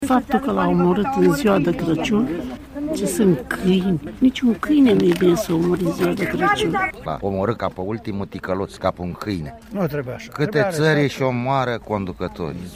Câteva persoane discută aprins la câțiva pași de mormânt.
Deși au existat părți rele și părți bune în Epoca de Aur, spun ei cu amărăciune în voce, felul în care poporul român a ales să-și schimbe conducătorul, în decembrie 1989, executându-l sumar, a fost unul nedrept.